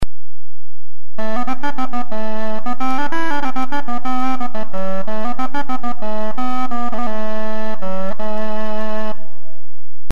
Early Music - Kortholt, a double reeded windcap instrument producing a characteristic buzzing sound
The kortholt (pronounced "kort-holt") is part of the wider family of renaissance "windcap" instruments, using a double reed inside a windcap to produce the characteristic windcap buzzing sound.
Kortholt Sound Clips